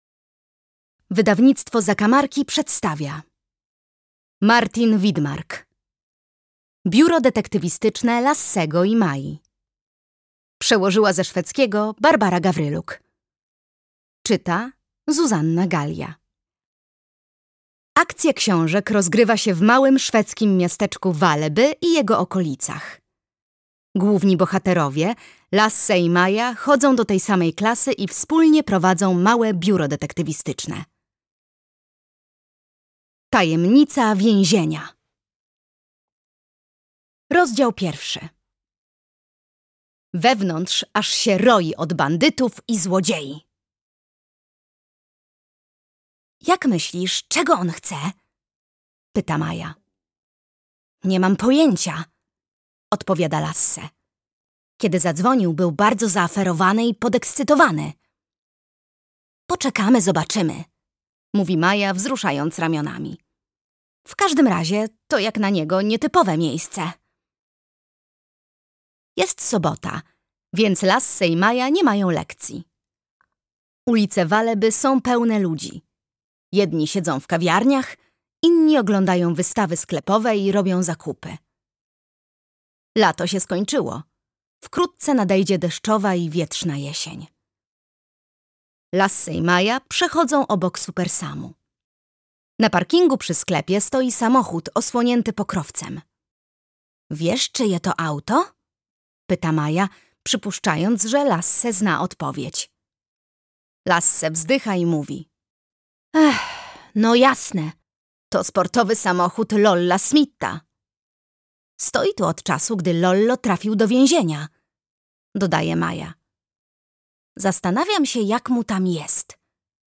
Biuro Detektywistyczne Lassego i Mai. Tajemnica więzienia - Martin Widmark - audiobook